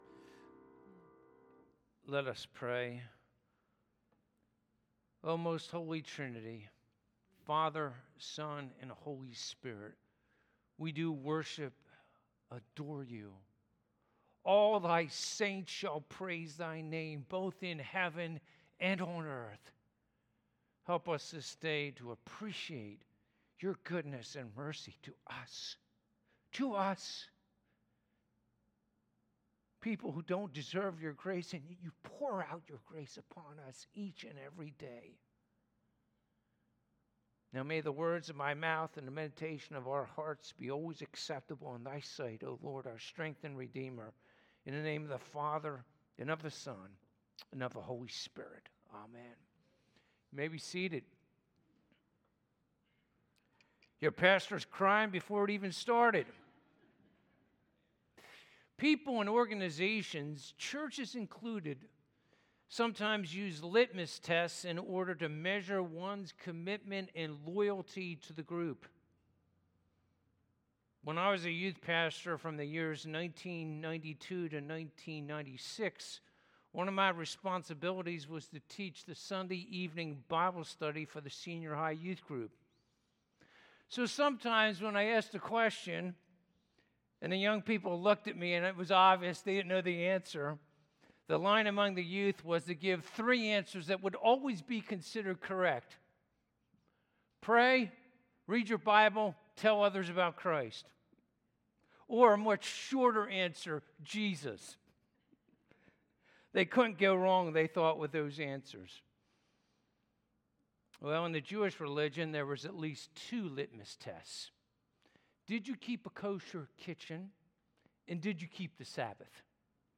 Passage: Luke 6: 1-11 Service Type: Sunday Morning « Jesus